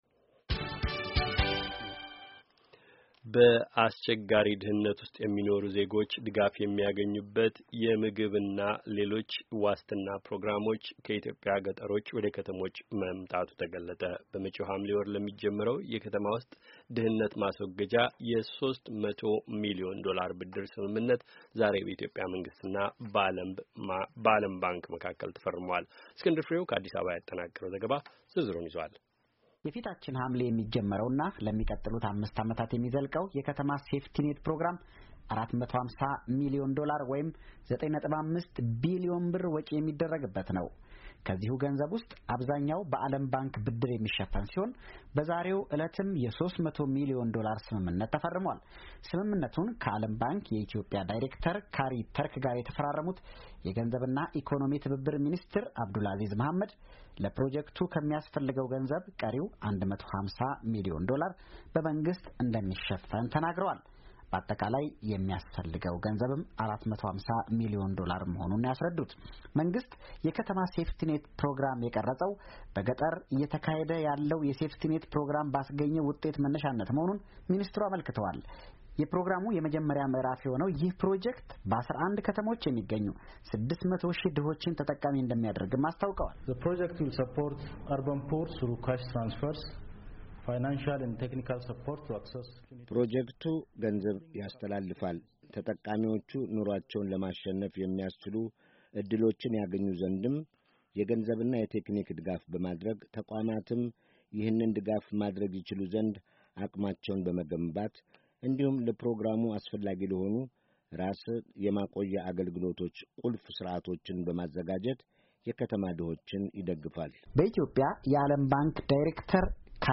ከአዲስ አበባ ያጠናቀረው ዘገባ ዝርዝሩን ያዳምጡ።